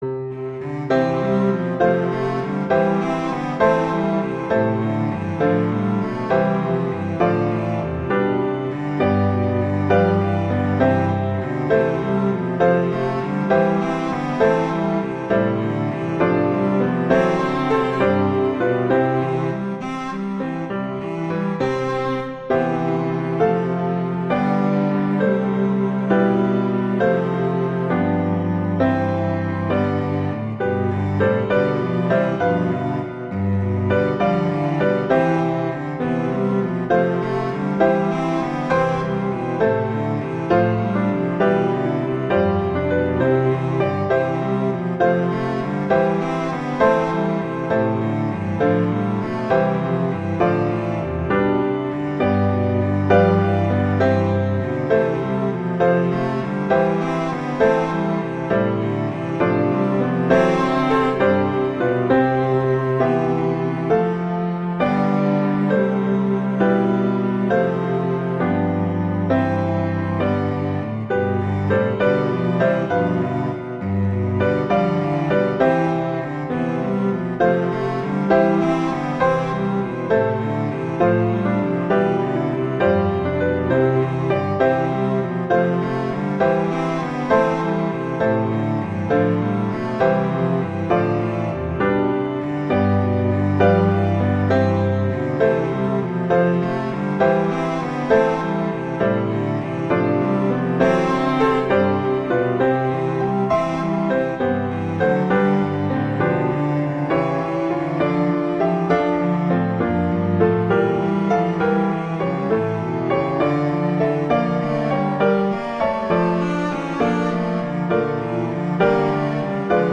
arrangements for two cellos and piano